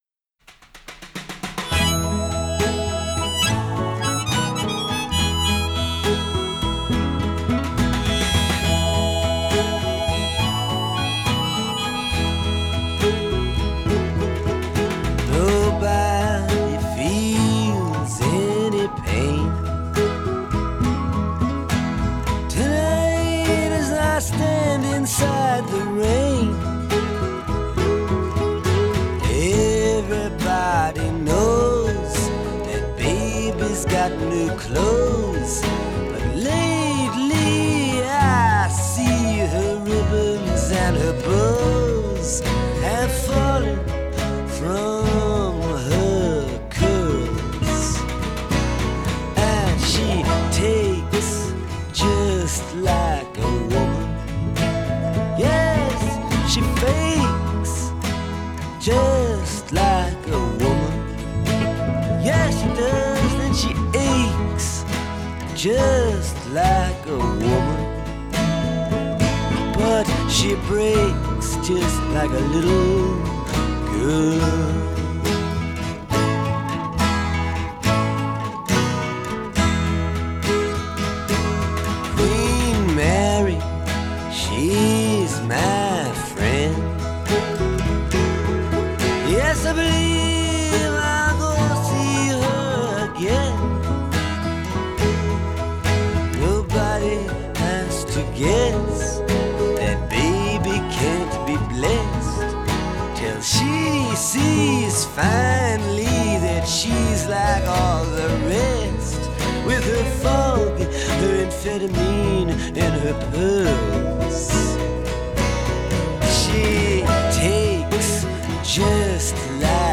studio outtakes